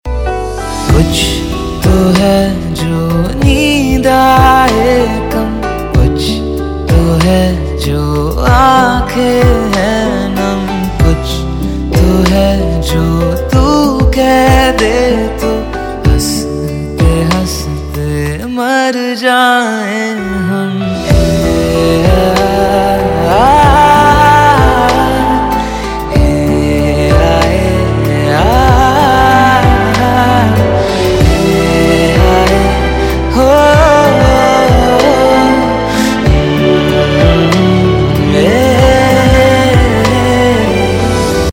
Bollywood - Hindi